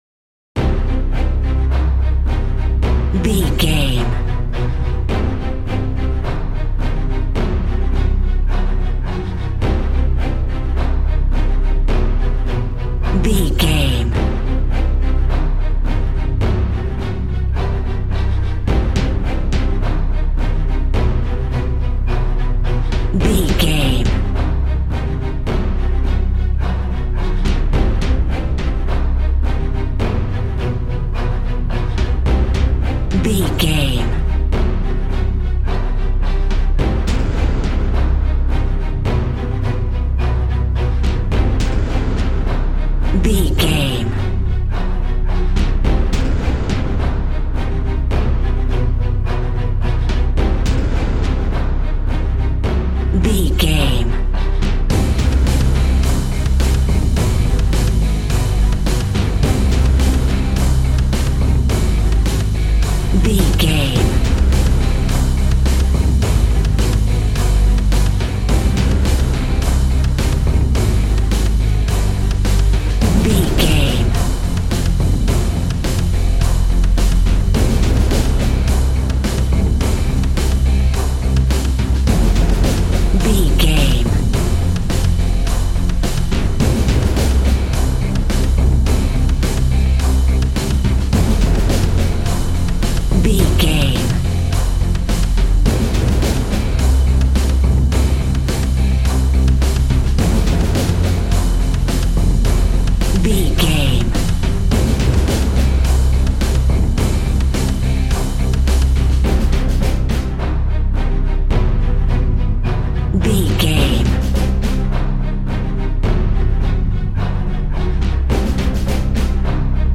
Epic / Action
Fast paced
In-crescendo
Uplifting
Ionian/Major
strings
brass
percussion
synthesiser